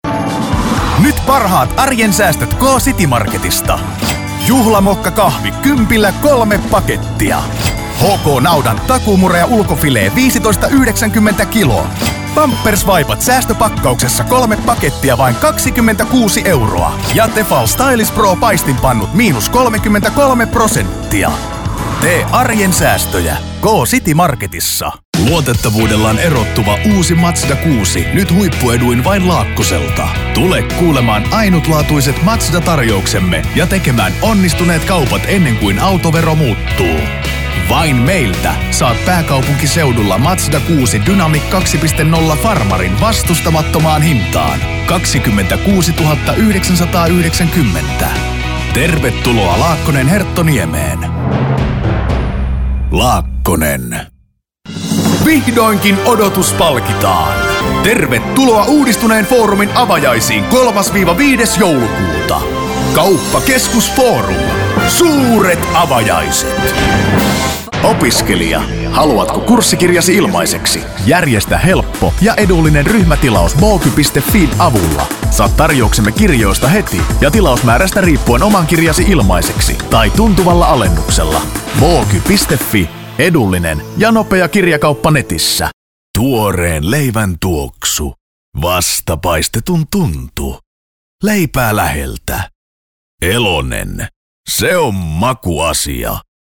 Native finnish professional voiceover artist with a warm versatile voice for all kind of voiceover work.
Sprechprobe: Werbung (Muttersprache):
I record using a very highend recording gear and signal chain.